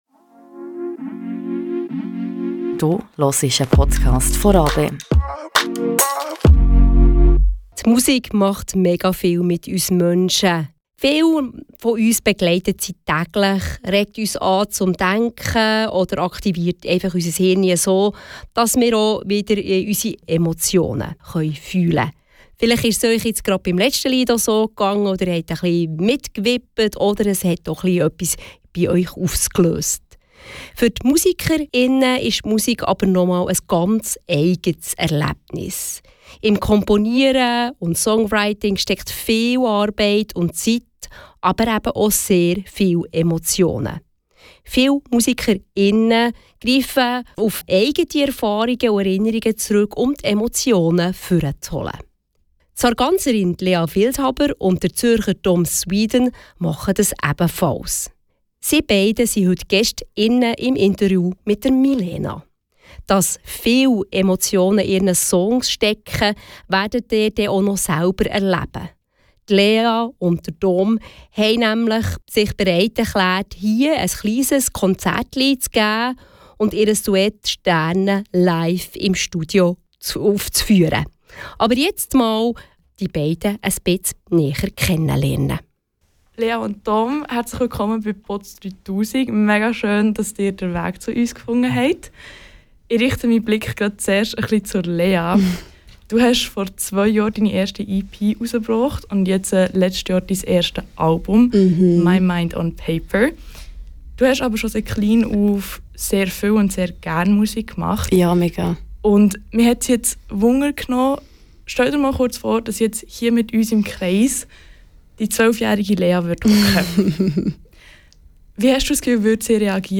Sänger aus Zürich waren bei Botz3000 zu Besuch und gaben uns ein kleines "Privat-Konzertli" mit ihrem Accoustic Song "Sterne".